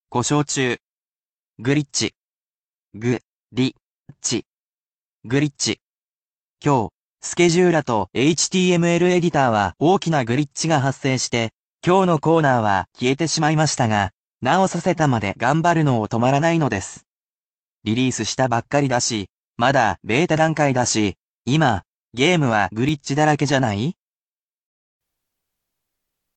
I will also read aloud the sentences for you, however those will be presented at natural speed.